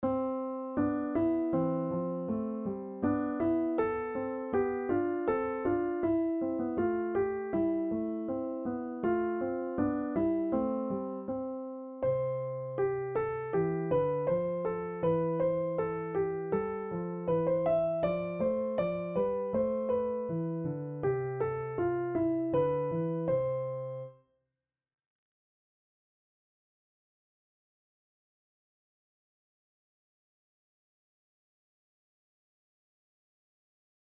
This is a mini tutorial on how to write a two-voice crab canon.